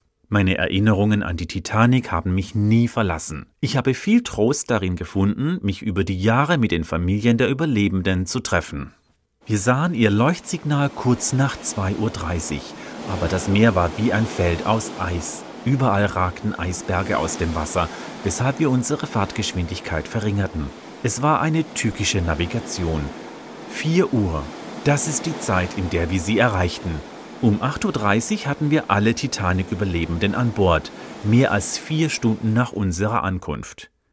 Voice-Over
Audio Guide - German